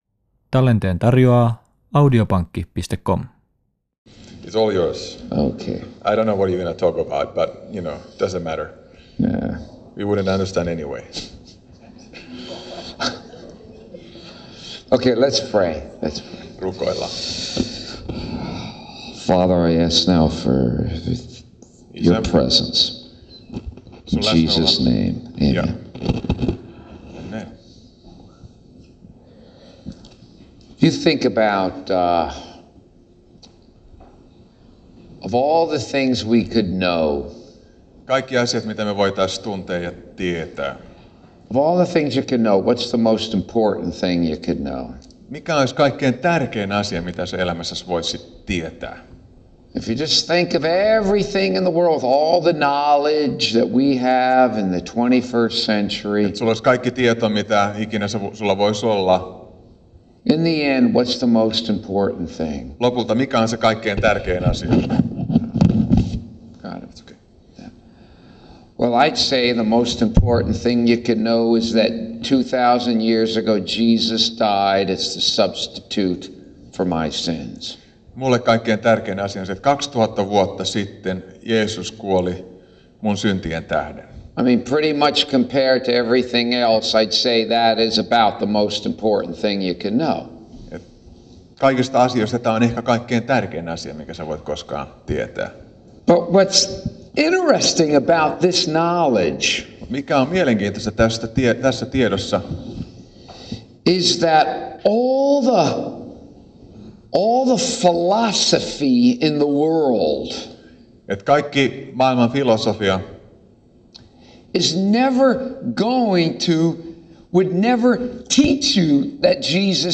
sermon
takes us to a mental travel with his lovely nasal accent.